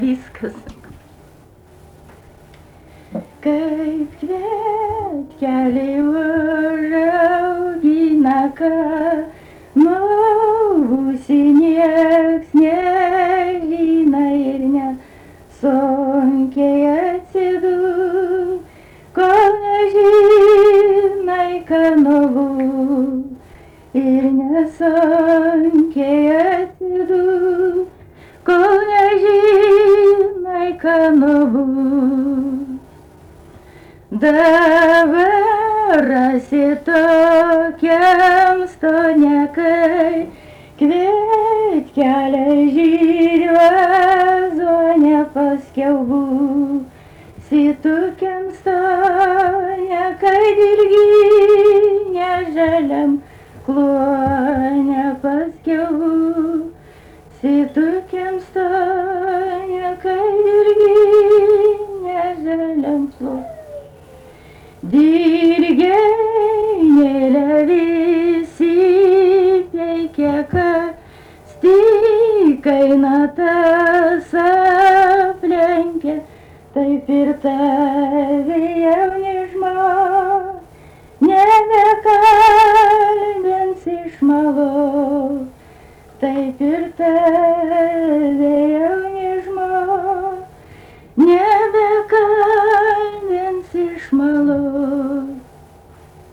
Dalykas, tema daina
Erdvinė aprėptis Krakės
Atlikimo pubūdis vokalinis